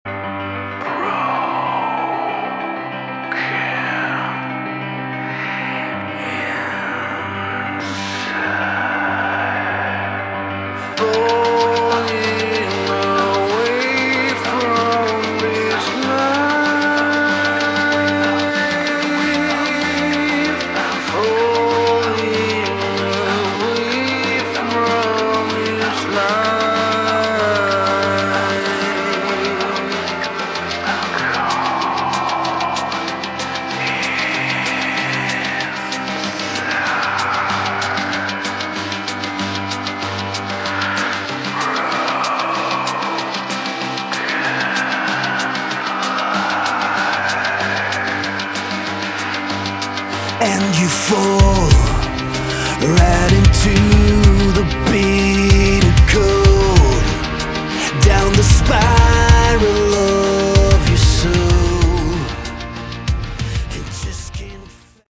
Category: Modern Hard Rock
vocals
bass
keyboards, guitars
drums